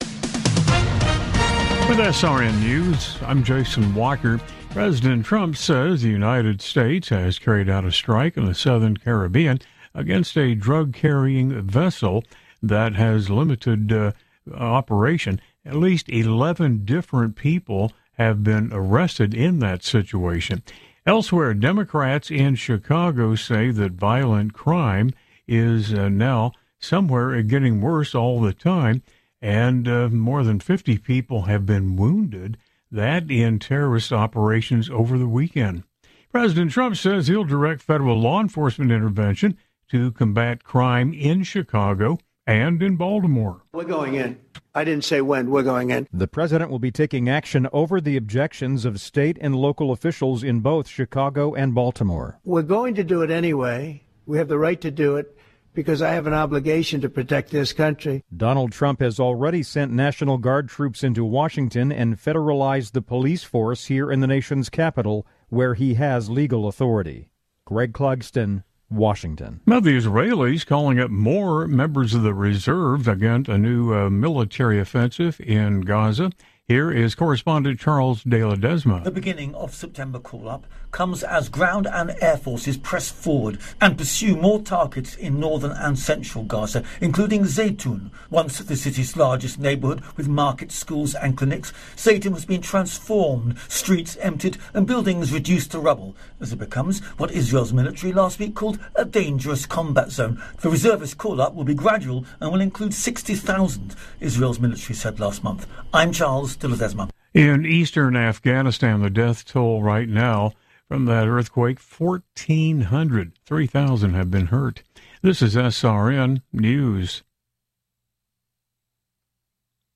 News stories as heard on SRN Radio News.